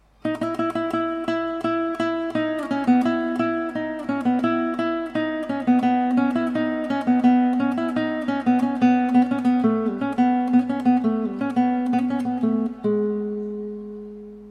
misket_duz.mp3